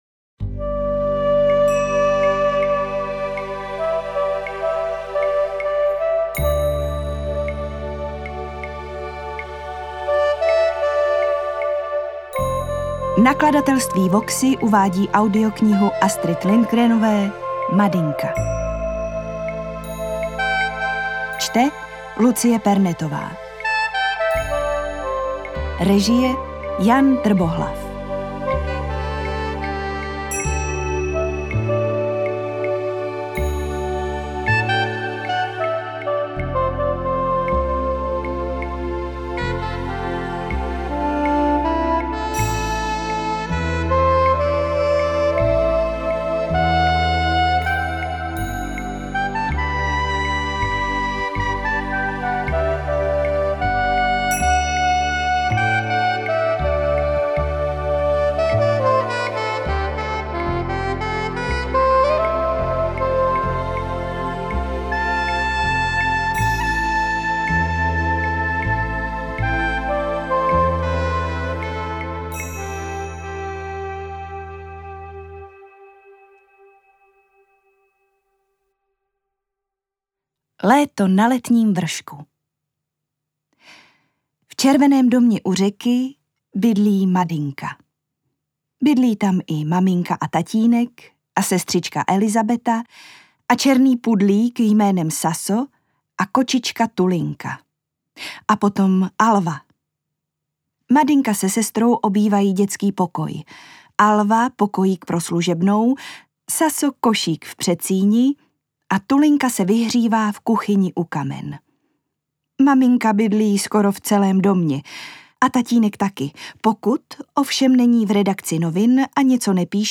AudioKniha ke stažení, 9 x mp3, délka 4 hod. 18 min., velikost 353,9 MB, česky